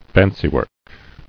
[fan·cy·work]